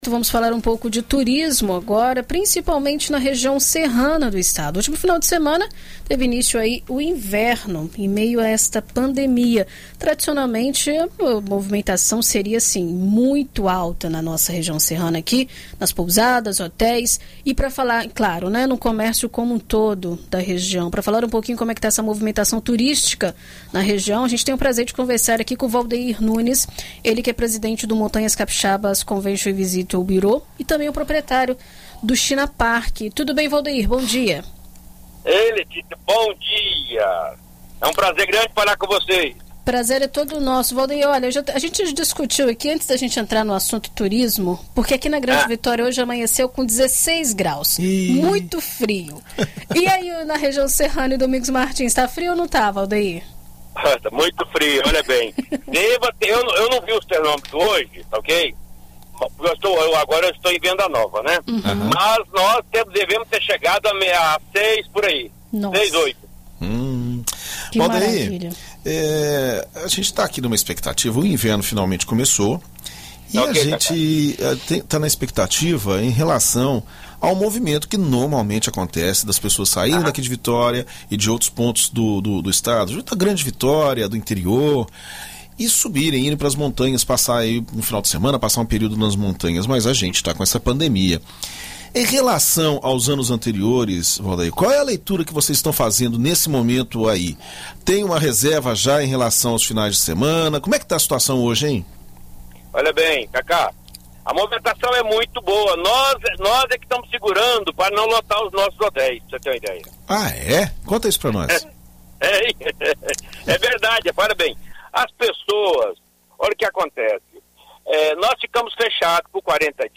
Confira mais sobre a situação do setor turístico das montanhas capixabas na entrevista: